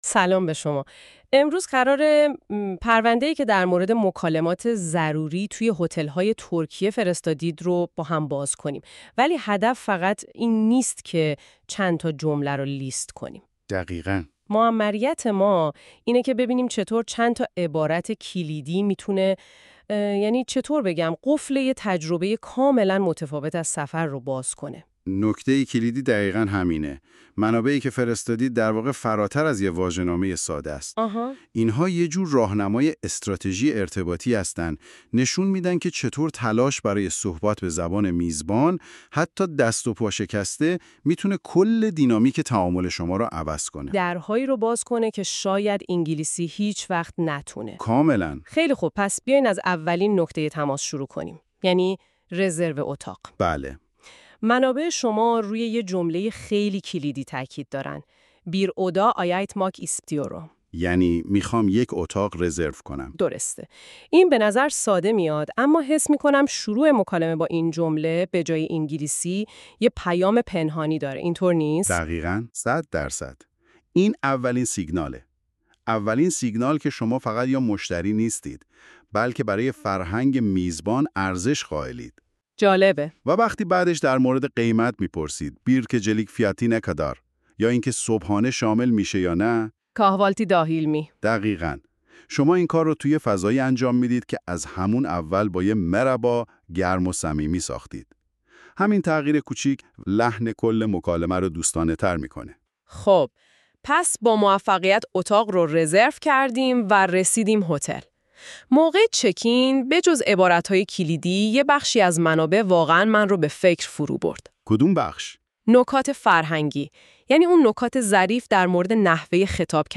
turkish-hotel-conversation.mp3